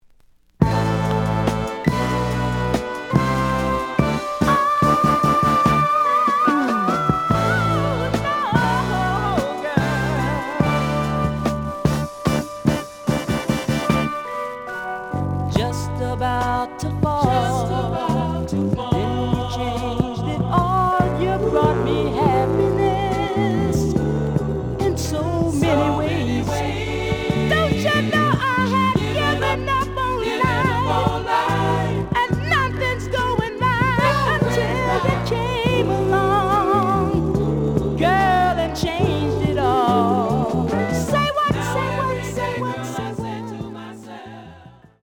The audio sample is recorded from the actual item.
●Genre: Soul, 70's Soul
Some noise on A side.